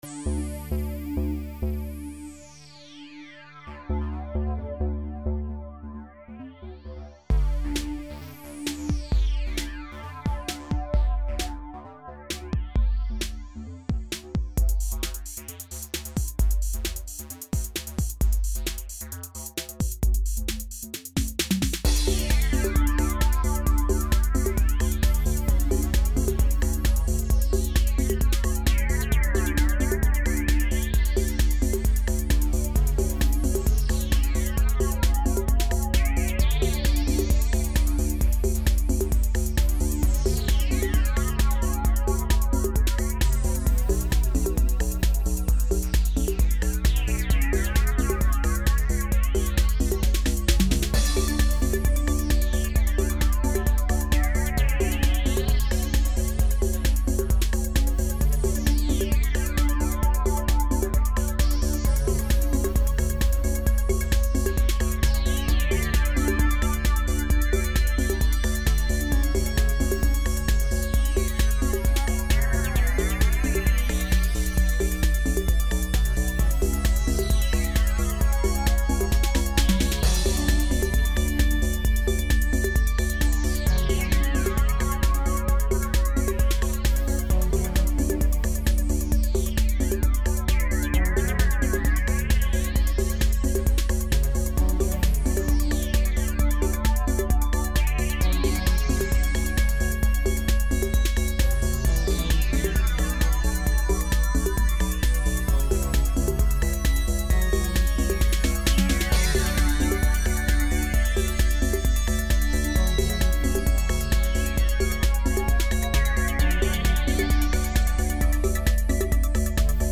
music made by me on my computer over the years.